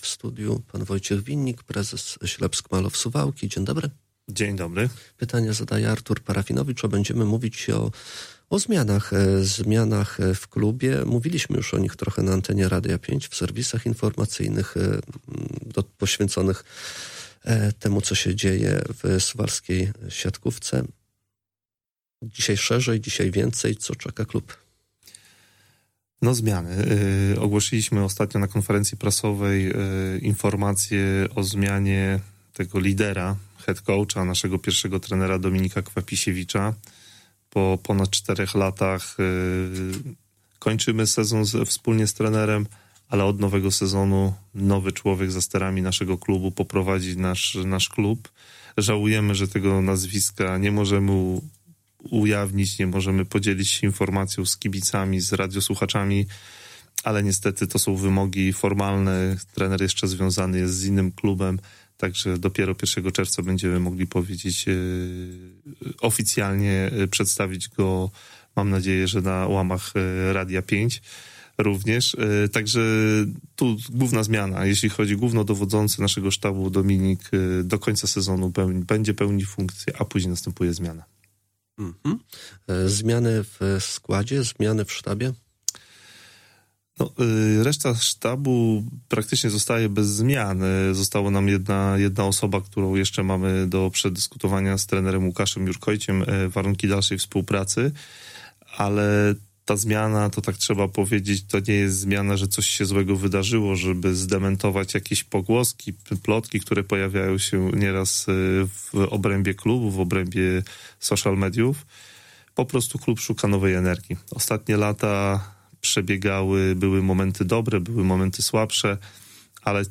Cała rozmowa poniżej: https